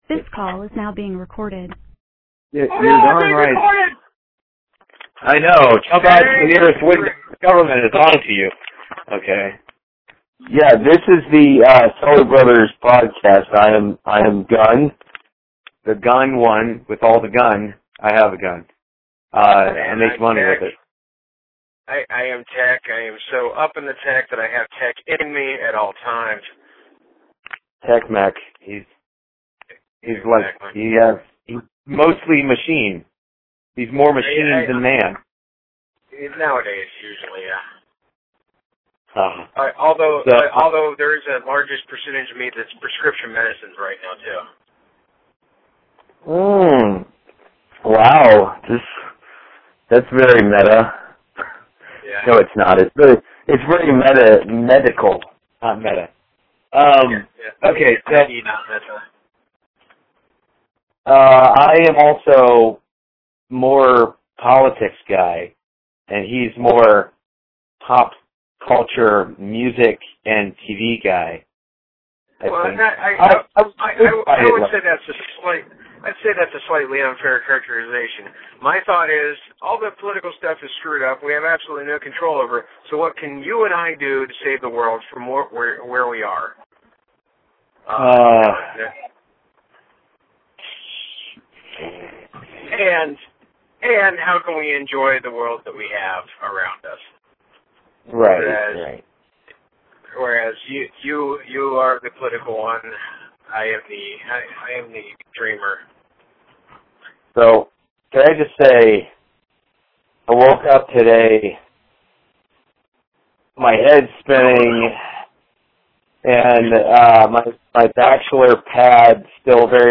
I actually have an intro prepped, One of the two of us just got hitched and the other one has a generally insanely busy life - but we want to make this podcast work - here is the rough audio.